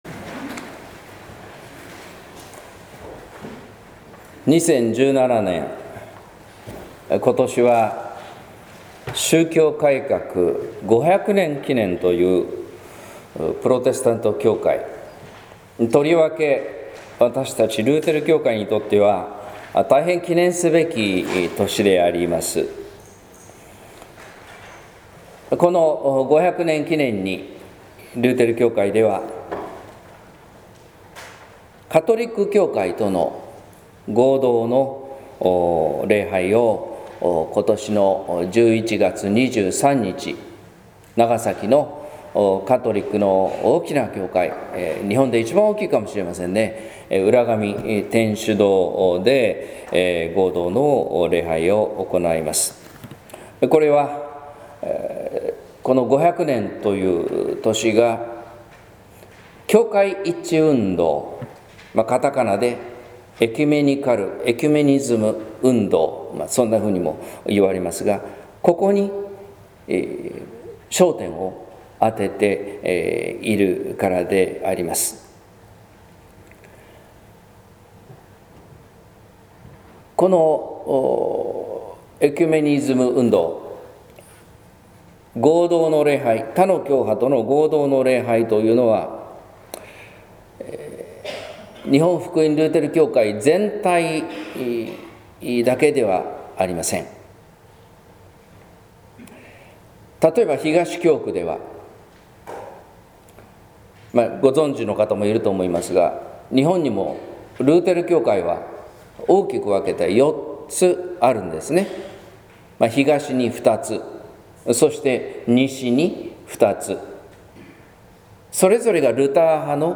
説教「私を遣わしてください」（音声版） | 日本福音ルーテル市ヶ谷教会